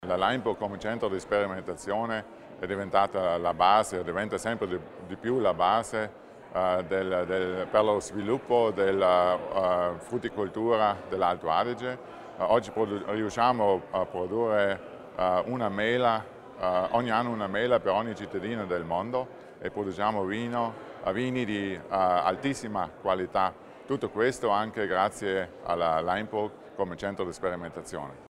L'Assessore Arnold Schuler evidenzia le sfide future del Centro Sperimentazione di Laimburg
I 40 anni del Centro di sperimentazione agraria e forestale Laimburg a Vadena sono stati celebrati oggi (21 novembre) all'Istituto agrario di Ora con un simposio davanti a quasi 200 persone.